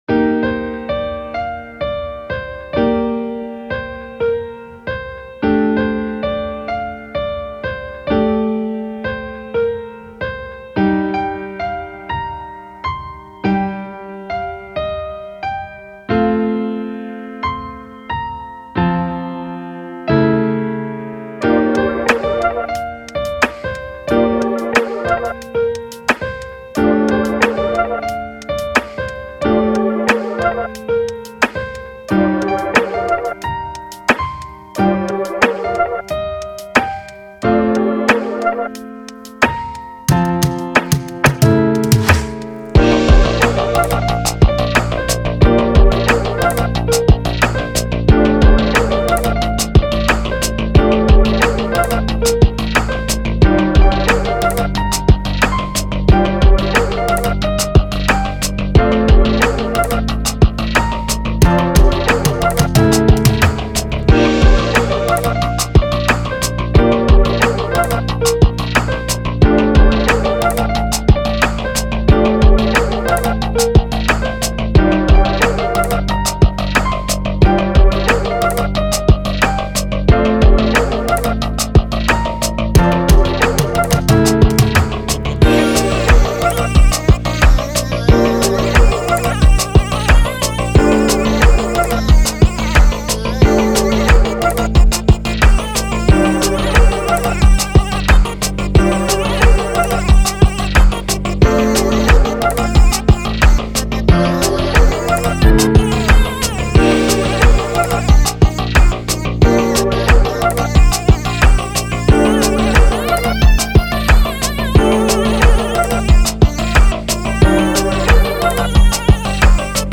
あかるい